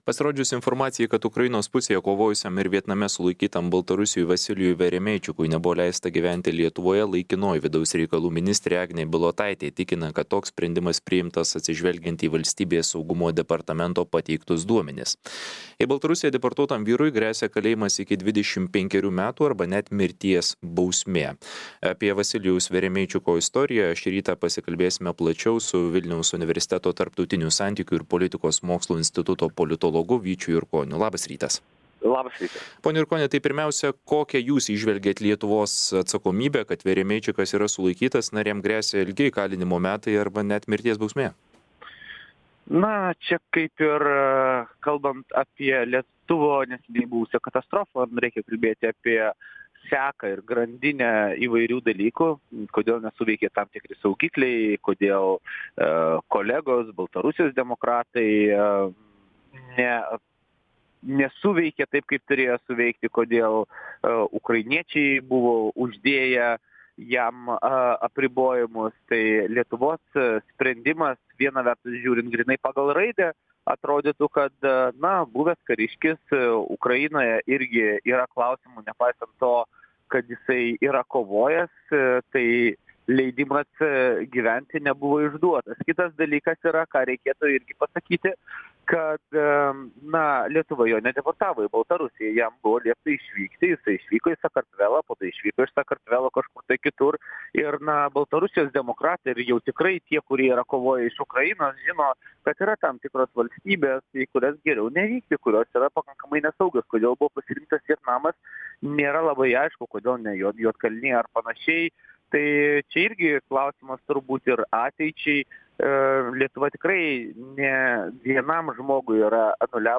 Pokalbis